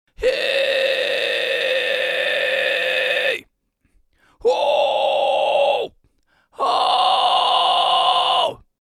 Le fry scream
Fry Scream simple basique
16Fry-Scream-simple-basique.mp3